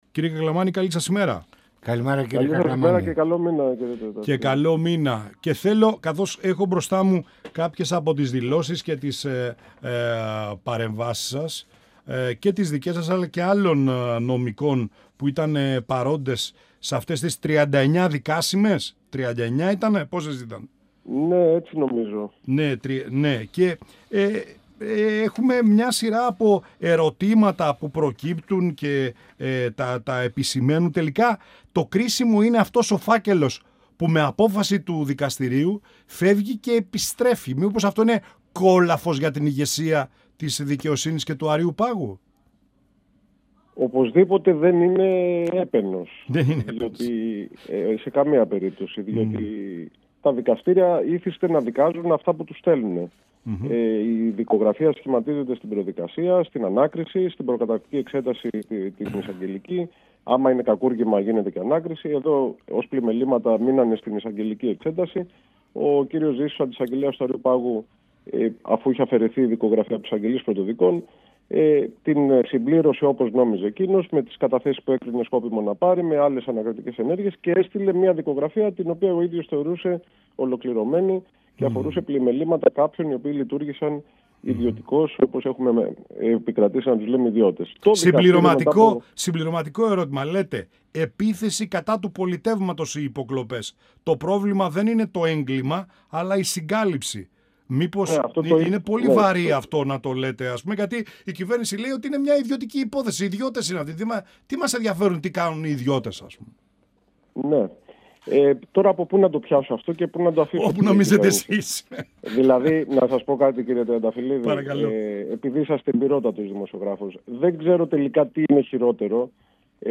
Πανοραμα Επικαιροτητας Συνεντεύξεις